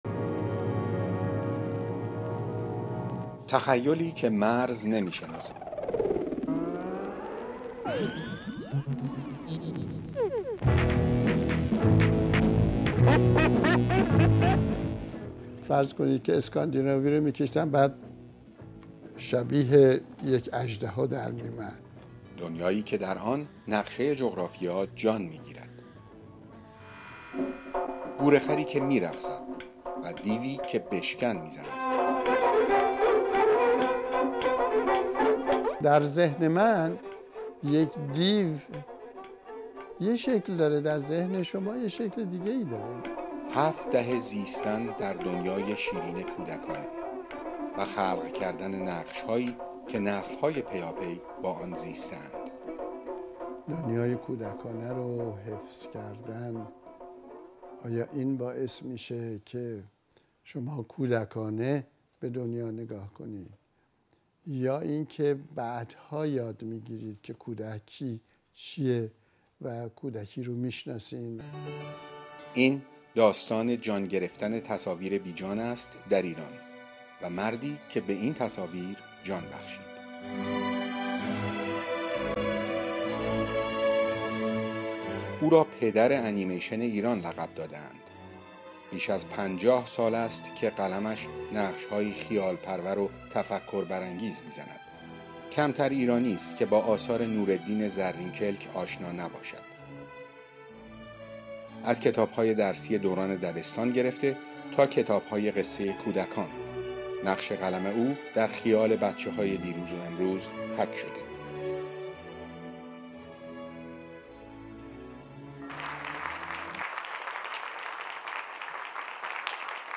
In this short documentary, discover how one man’s passion for storytelling, art, and education shaped a generation of animators — and how his influence still echoes through global animation today. 🎨 Featuring: rare artworks, early animation clips, and insights into Zarrinkelk’s creative philosophy.